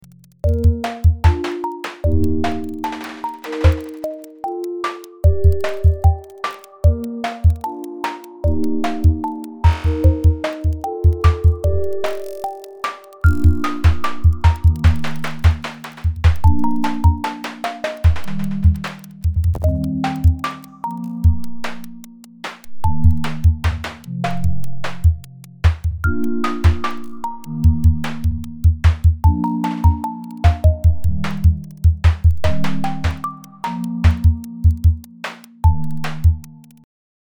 まず最初は以下のように「linear」を指定して、画面内を左右に動かしてみた。